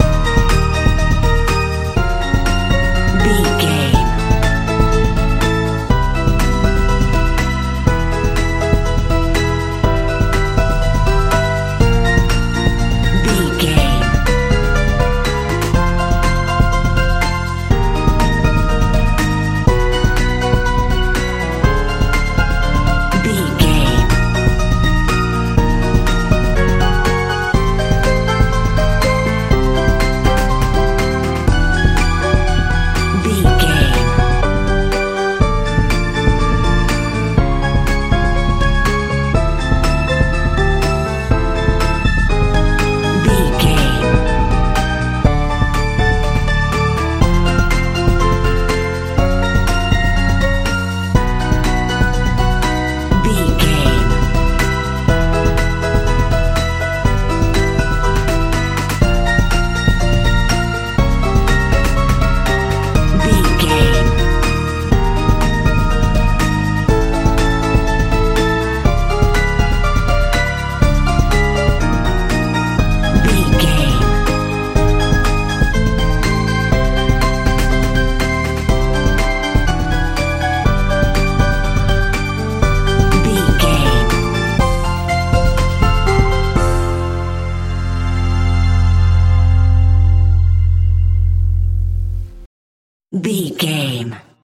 lite modern pop
Ionian/Major
E♭
hopeful
joyful
piano
synthesiser
bass guitar
drums
80s
mechanical
hypnotic